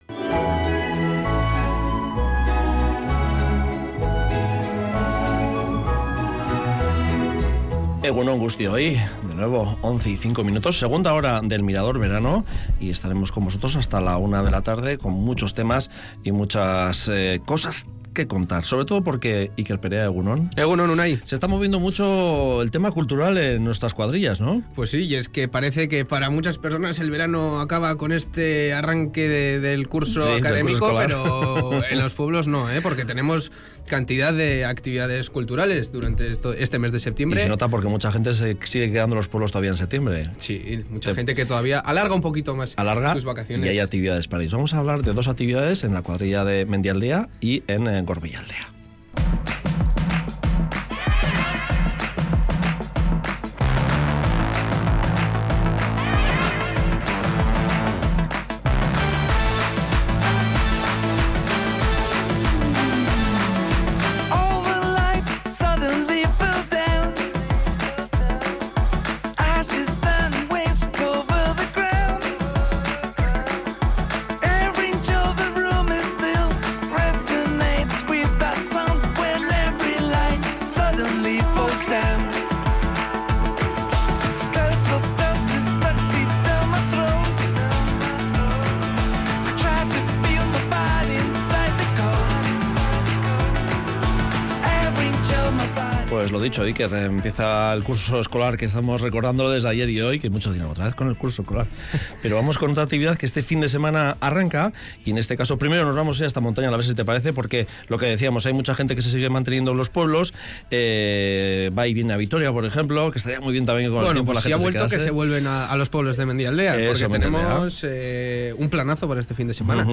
Los organizadores de ambos festivales nos explican el programa que han planteado.